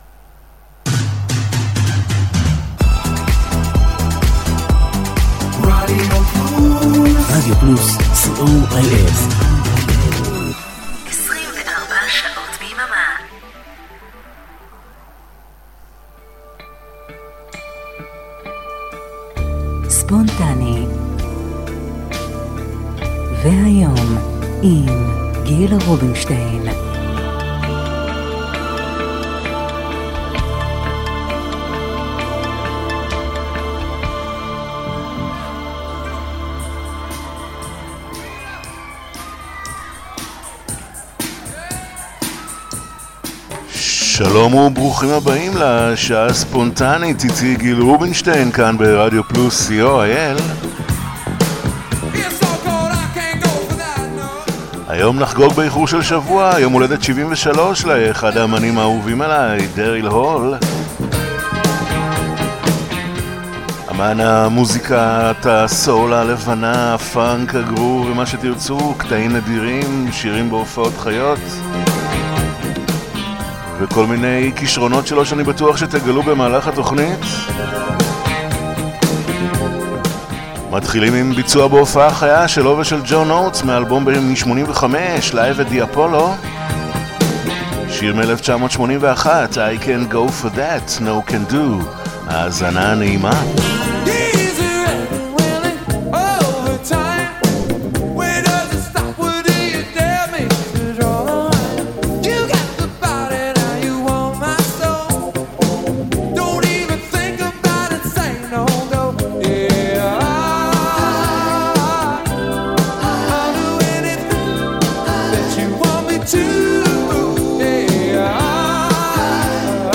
Live Version
Previously Unreleased Mix